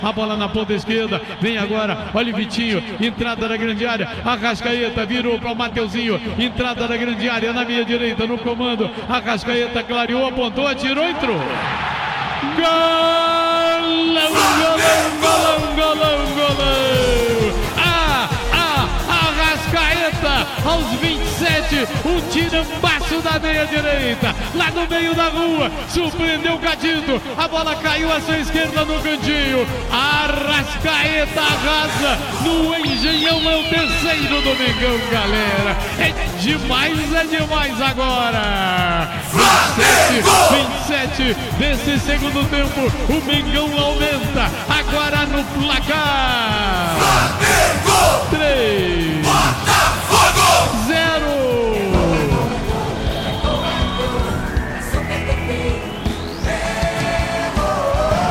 Ouça os gols da vitória do Flamengo sobre o Botafogo pelo Carioca com a narração do Garotinho